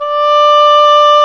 Index of /90_sSampleCDs/Roland L-CDX-03 Disk 1/CMB_Wind Sects 1/CMB_Wind Sect 2
WND OBOE3 D5.wav